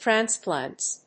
/træˈnsplænts(米国英語)/